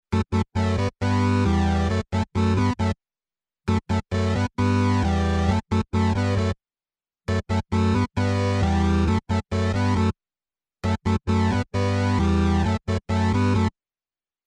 8 bit Gaming Musik
Tempo: schnell / Datum: 15.08.2019